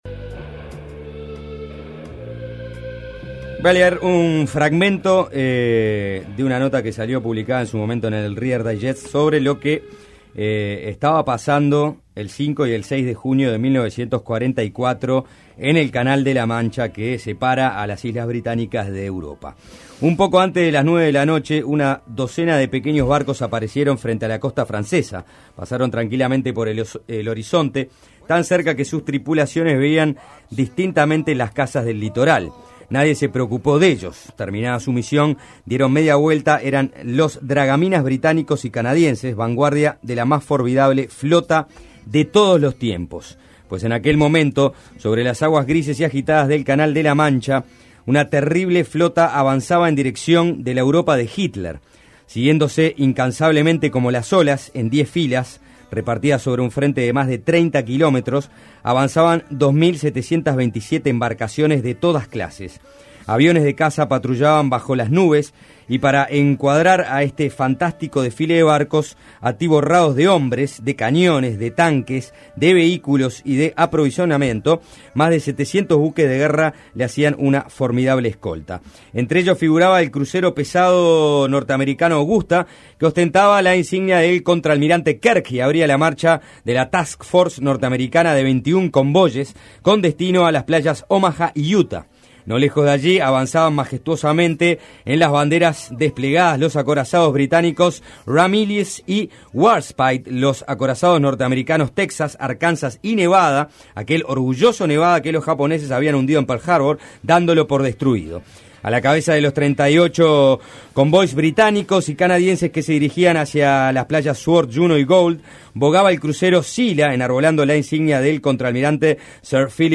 Descargar Audio no soportado Ver video completo Sobre el tema entrevistamos al profesor